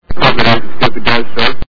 The sound bytes heard on this page have quirks and are low quality.